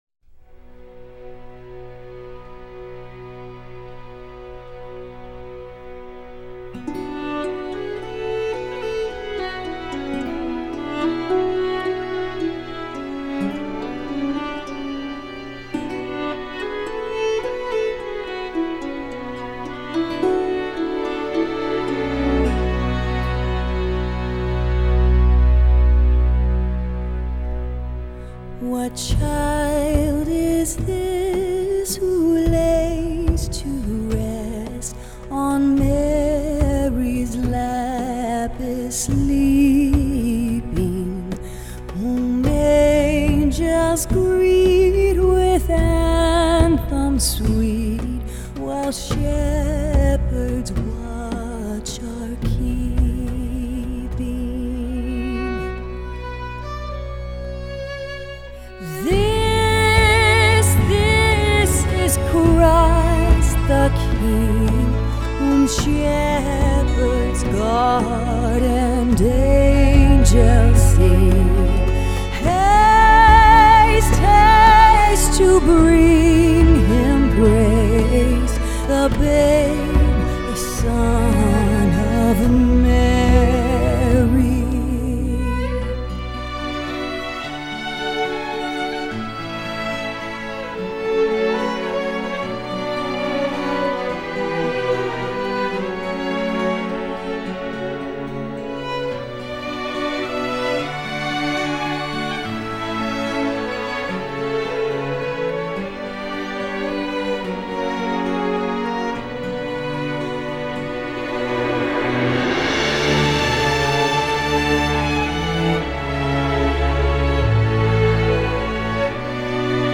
她的嗓音异常优美
甜美的乐感能抓住每个人的心灵
专辑流派：Country